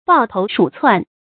注音：ㄅㄠˋ ㄊㄡˊ ㄕㄨˇ ㄘㄨㄢˋ
抱頭鼠竄的讀法